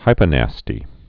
(hīpə-năstē)